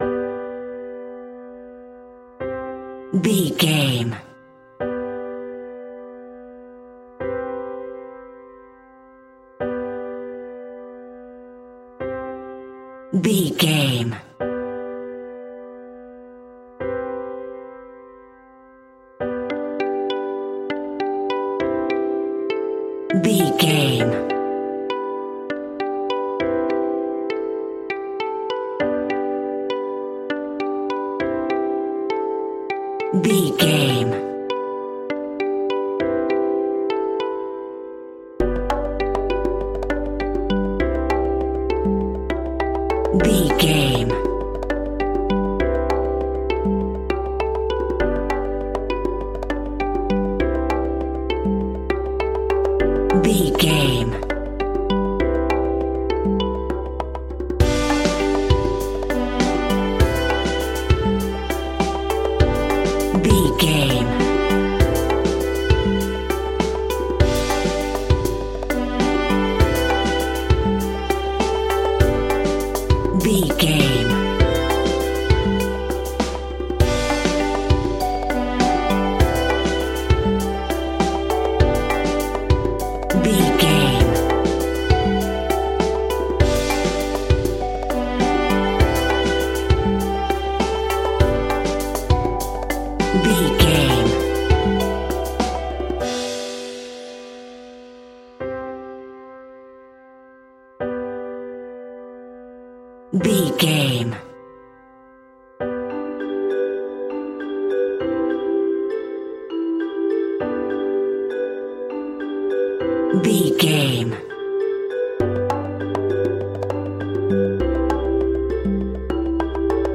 Ionian/Major
pop rock
indie pop
energetic
uplifting
upbeat
groovy
guitars
bass
drums
piano
organ